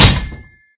sounds / mob / zombie / metal3.ogg
metal3.ogg